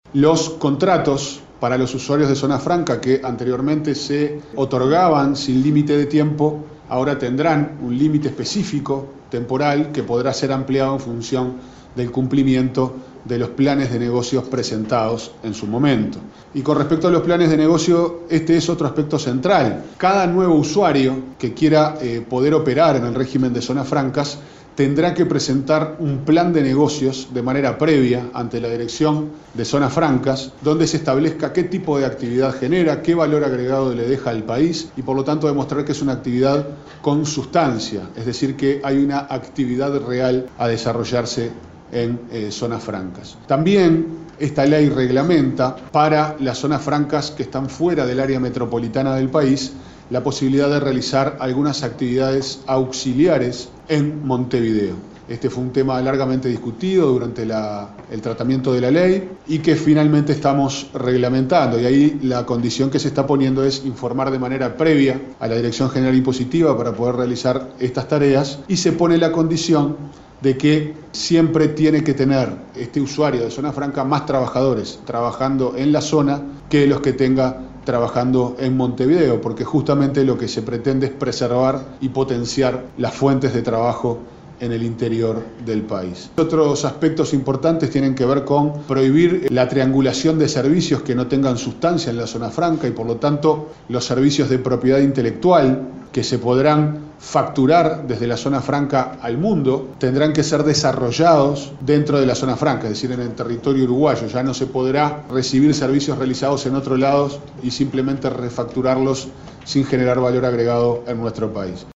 El sub secretario del Ministerio de Economía, Pablo Ferreri, se refirió al tema y explicó los nuevos cambios previstos.